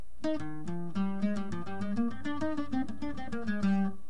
lamentira_requinto.mp3